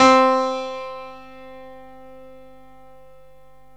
PIANO 0004.wav